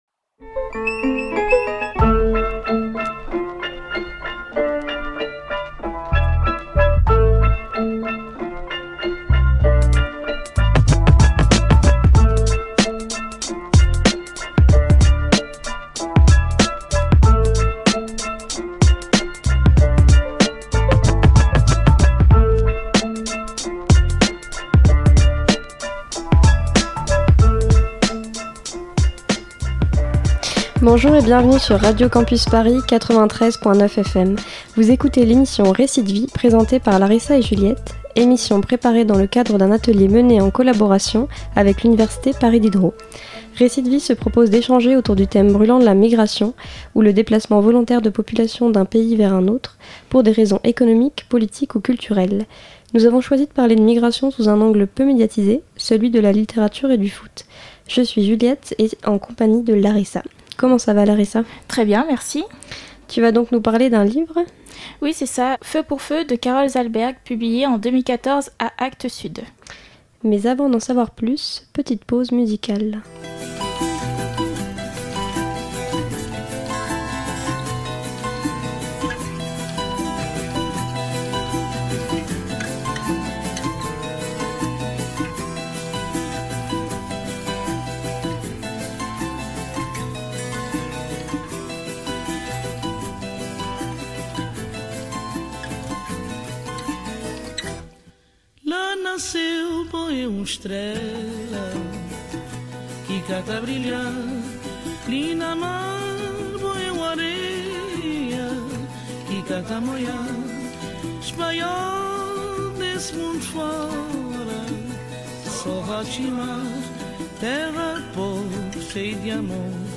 Après un cycle de 10 ateliers de formation intensive aux techniques radiophoniques, les étudiants de Paris 7 ont enregistré une émission dans les conditions du direct au sein des studios de Radio Campus Paris intitulée « Récits de vie ».